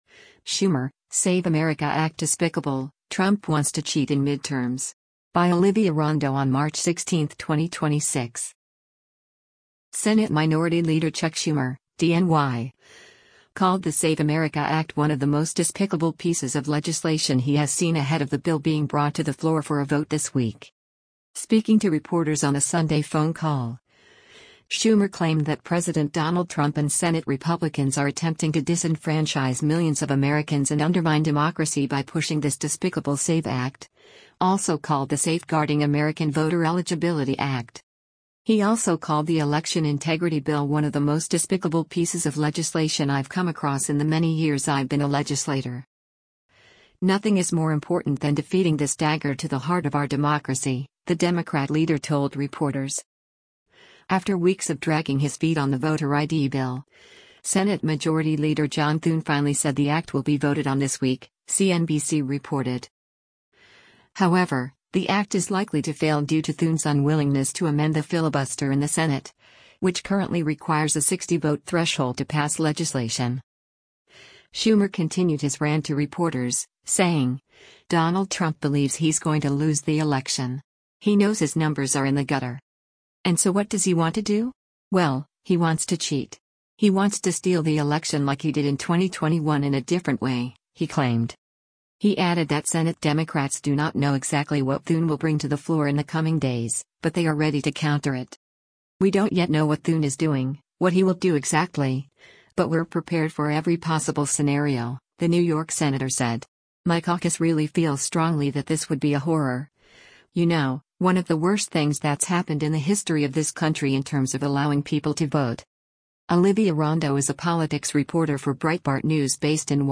Speaking to reporters on a Sunday phone call, Schumer claimed that President Donald Trump and Senate Republicans are attempting to “disenfranchise millions of Americans and undermine democracy by pushing this despicable SAVE Act,” also called the Safeguarding American Voter Eligibility Act.